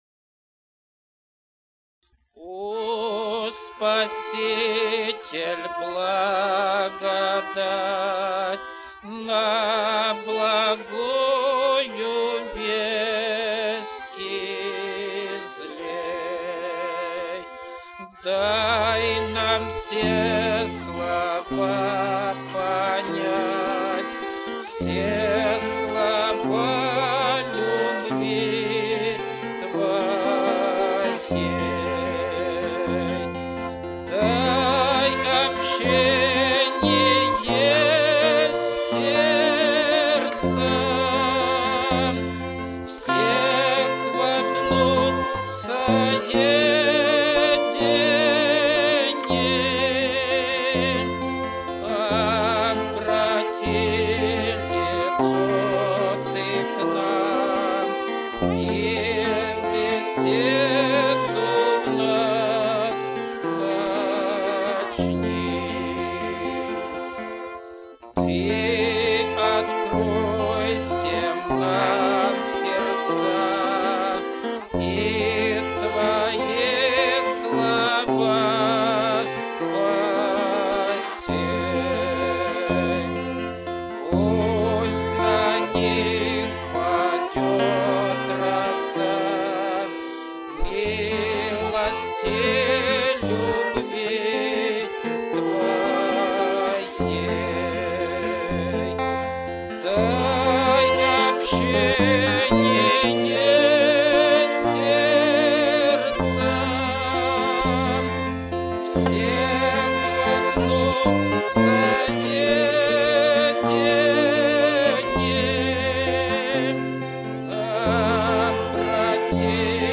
(Луки 15:1-2) Tagged with Воскресные Богослужения